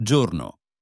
day JOR-noh